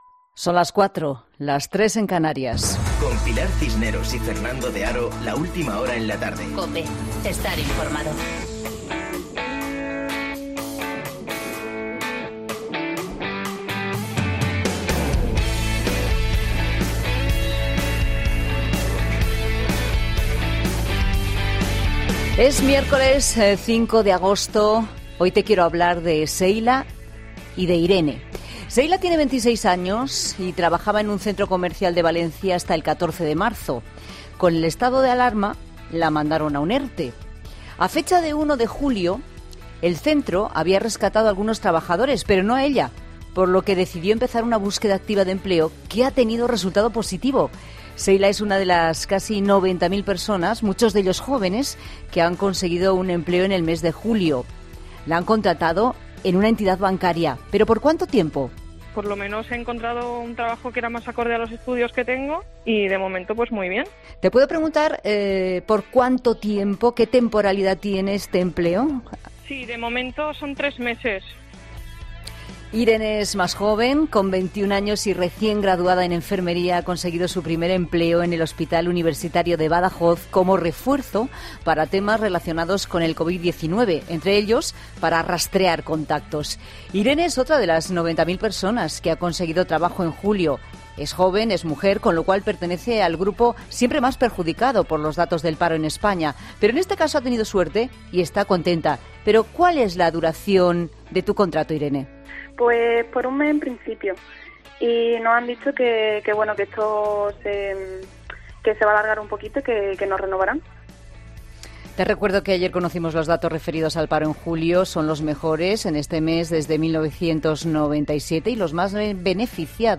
Monólogo de Pilar Cisneros